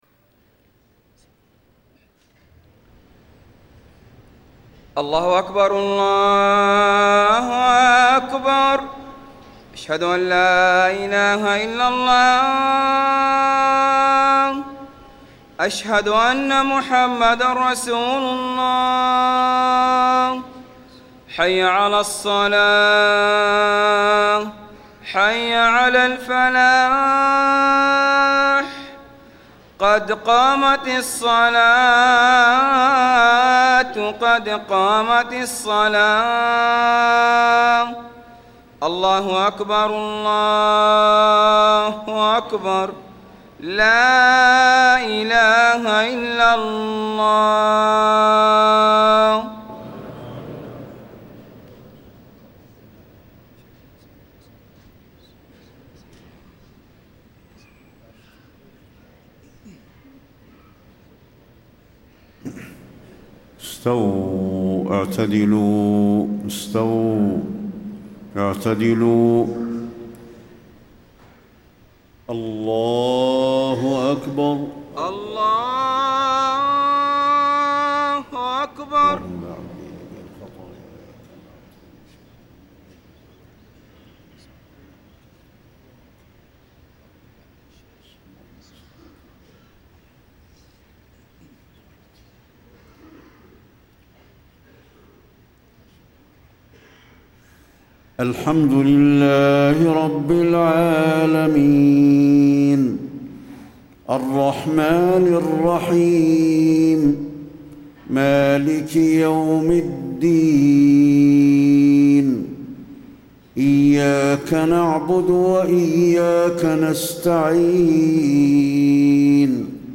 صلاة العشاء 9-7-1434هـ فواتح سورة الذاريات > 1434 🕌 > الفروض - تلاوات الحرمين